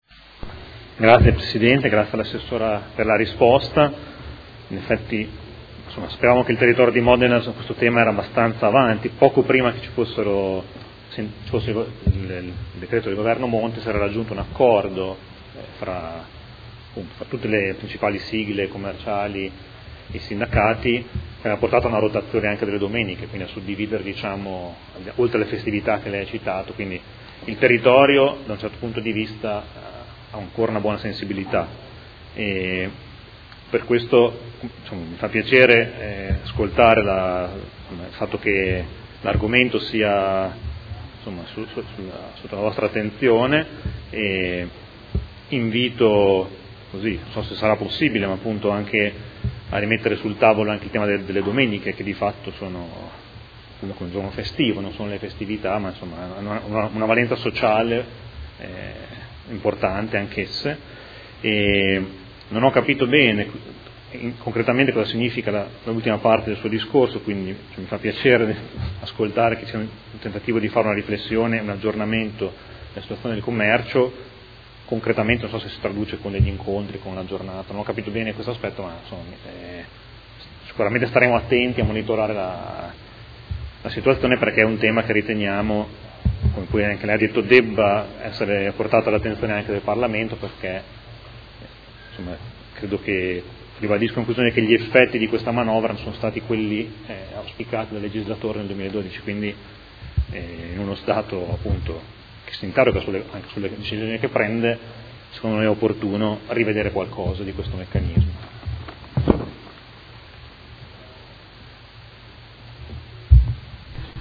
Seduta del 26/06/2017. Conclude interrogazione del Gruppo Movimento cinque Stelle avente per oggetto: Intervenire sulle liberalizzazioni